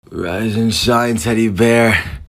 PLAY Teddy Bear Squeek
teddy-bear.mp3